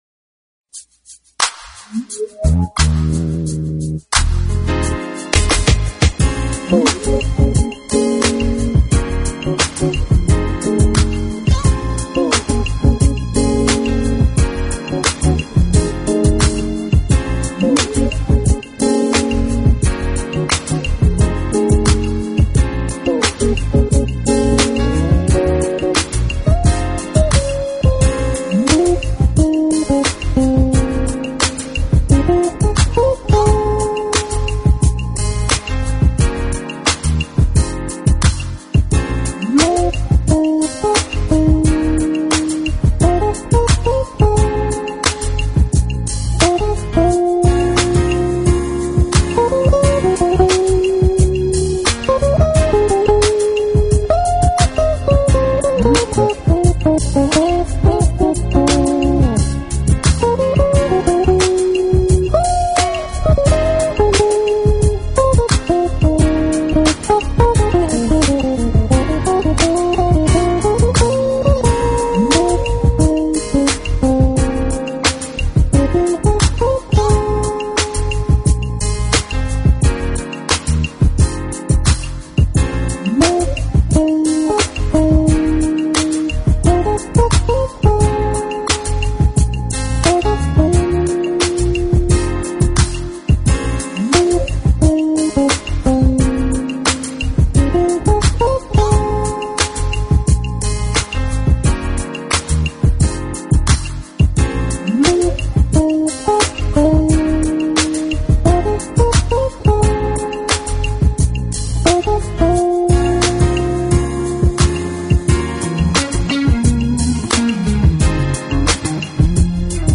smooth jazz/urban contemporary/quiet storm group